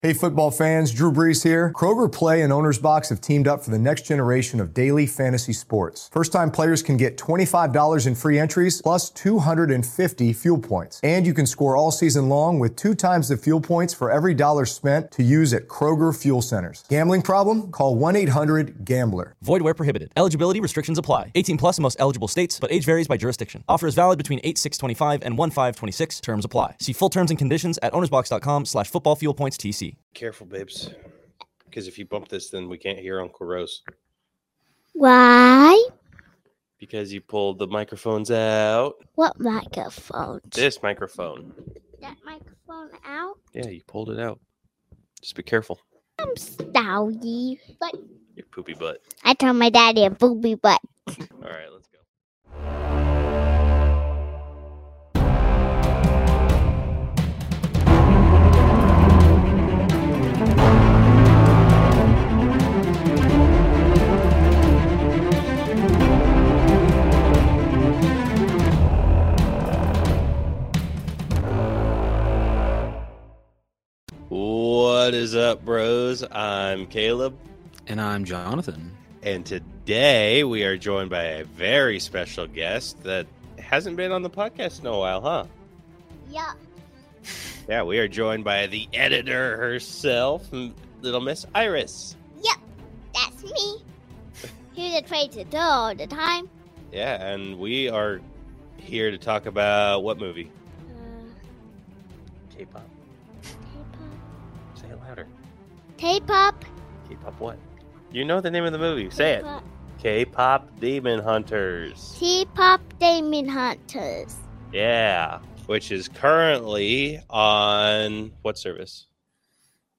The All Bro’s are two bros that enjoy a good film and all things superhero-related. They talk about all the things that they find interesting in the world of film and also talk about all their collectible purchases like Funko Pops, Blu-Ray Steelbooks, and also breakdown movies that they’ve seen to give them a final grade.